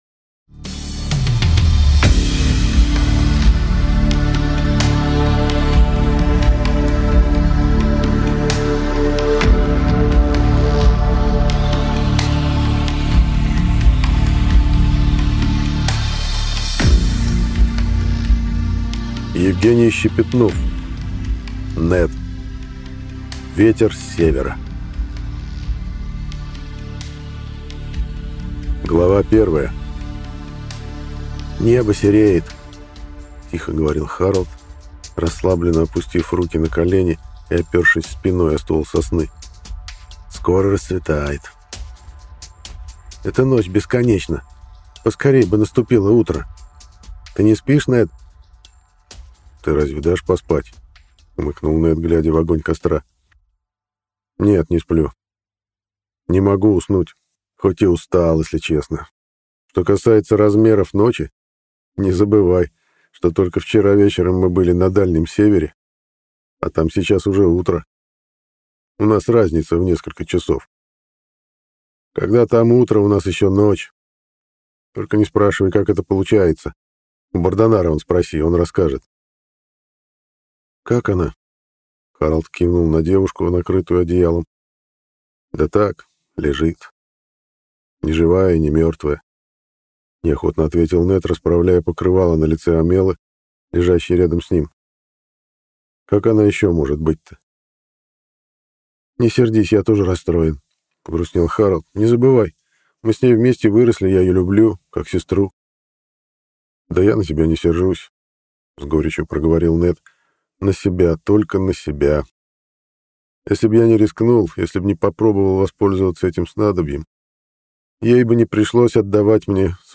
Аудиокнига Ветер с севера | Библиотека аудиокниг